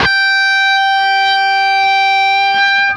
LEAD G 4 CUT.wav